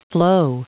Prononciation du mot flow en anglais (fichier audio)
Prononciation du mot : flow